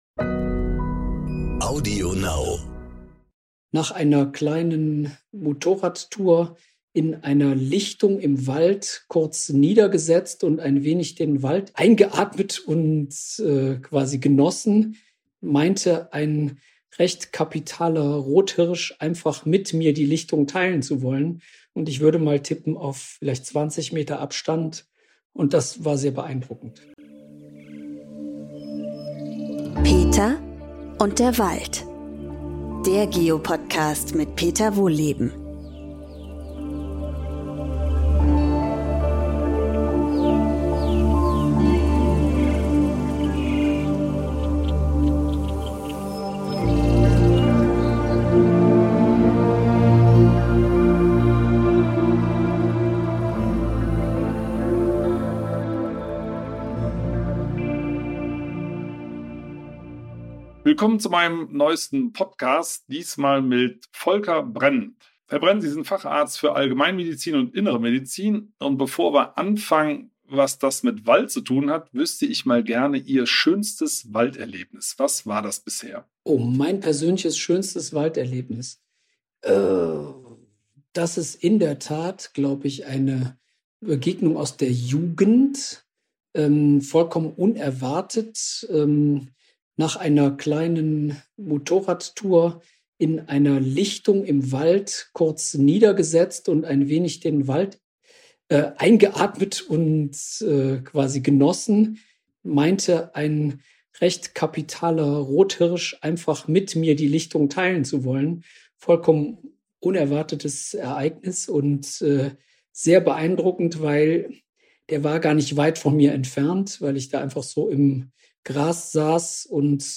befragt den Arzt und Borreliose-Experten